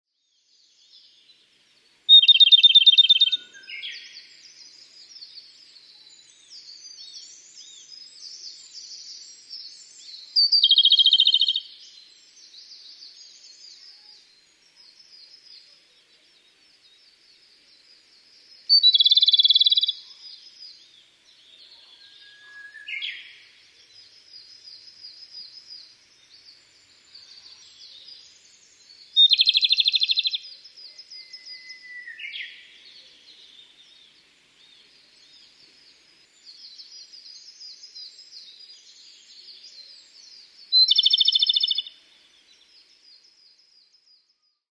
komadori_s1.mp3